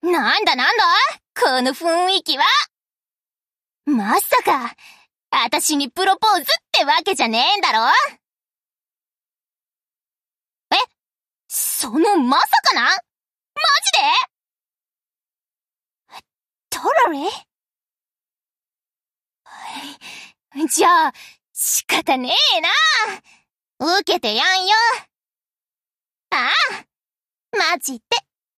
Ship Voice Phoenix Wedding.mp3